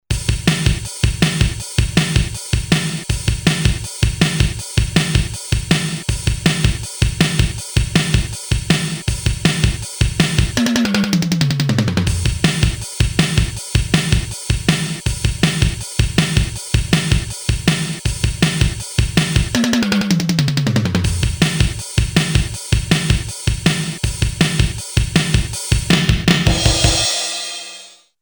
Early digital drum machine with internal sequencer.
edit WAVETABLE ROM contains 29 PCM sampled at 12 bits mainly classic percussions and a few bass sounds - each sample can be edited with pan position, pitch and played in MONO or POLYPHONIC mode.
heavy metal pattern
-12 bits crispy -internal waves